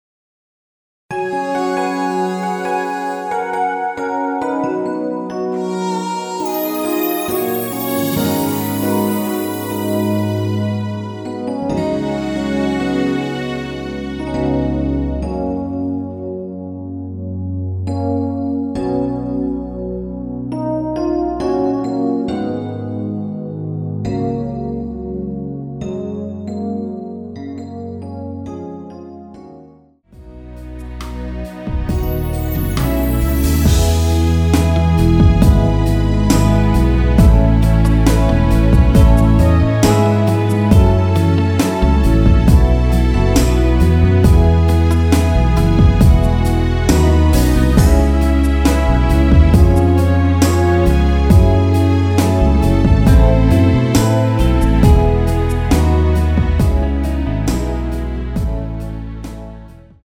원키에서(-1)내린 MR입니다.
F#
앞부분30초, 뒷부분30초씩 편집해서 올려 드리고 있습니다.
중간에 음이 끈어지고 다시 나오는 이유는
곡명 옆 (-1)은 반음 내림, (+1)은 반음 올림 입니다.